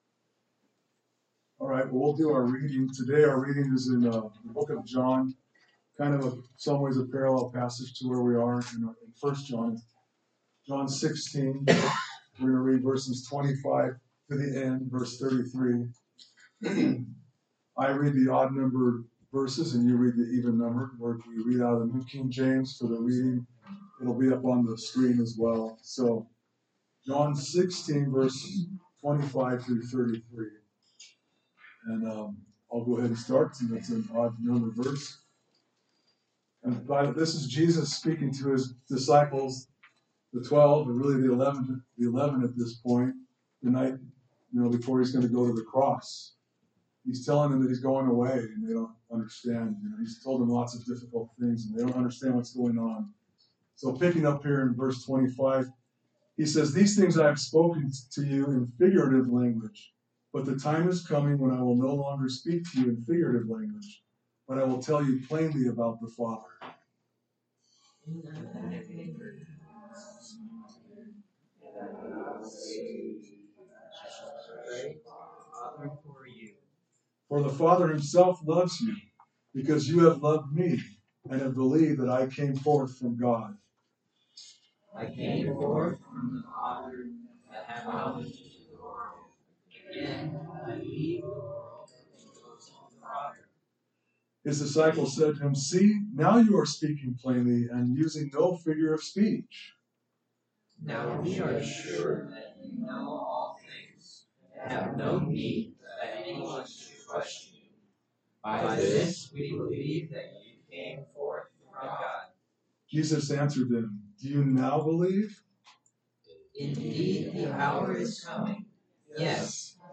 A message from the series "1 John."